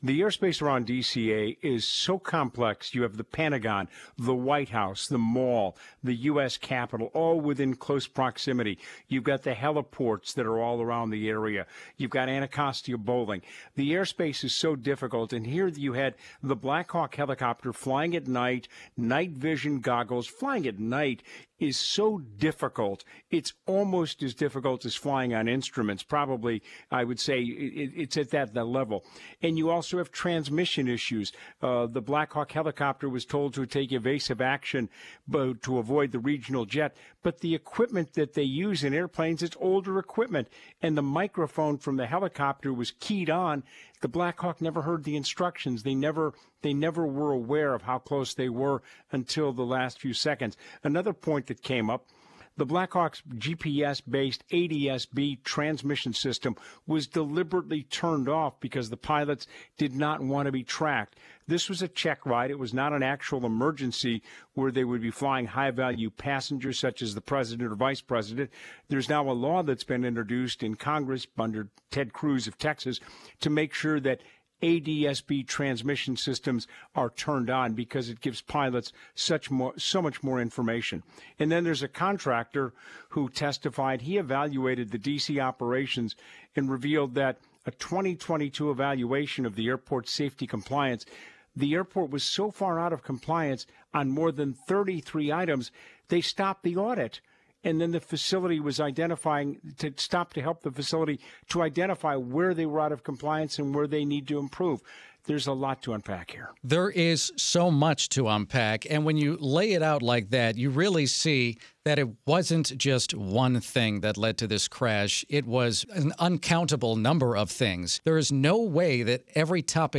who is also a pilot and aviation expert, breaks down the NTSB hearings into January's midair collision over the Potomac River near Reagan National Airport.